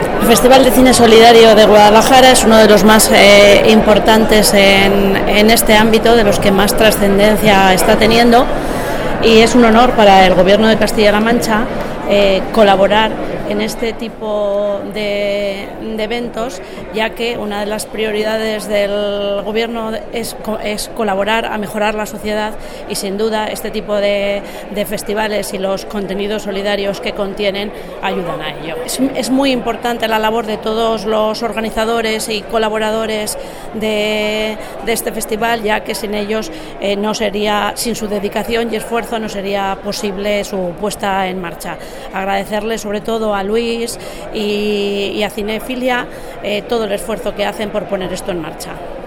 La viceconsejera de Educación, Universidades e Investigación, Maria Dolores López, habla de la importancia del FESCIGU